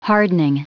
Prononciation du mot hardening en anglais (fichier audio)
Prononciation du mot : hardening